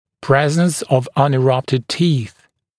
[‘prezns əv ˌʌnɪ’rʌptɪd tiːθ][‘преэзнс ов ˌани’раптид ти:с]наличие непрорезавшихся зубов